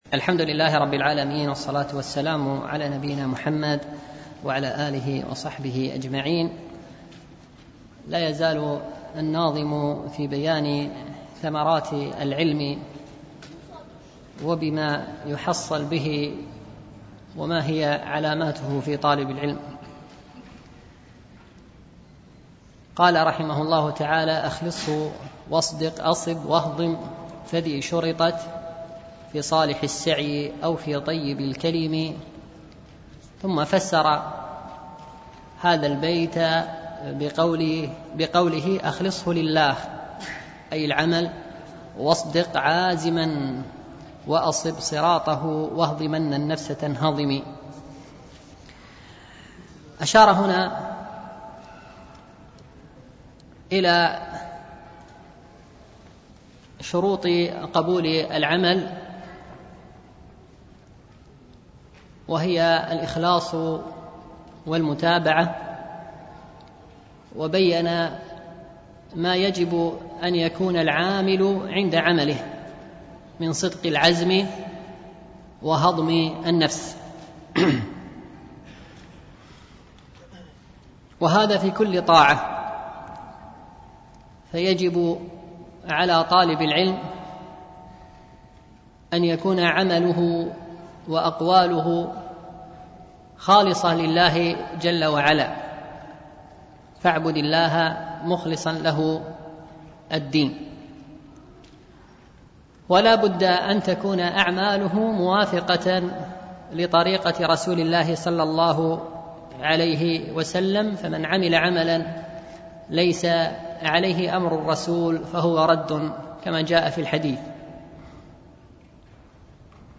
شرح المنظومة الميمية في الوصايا والآداب العلمية ـ الدرس الثاني عشر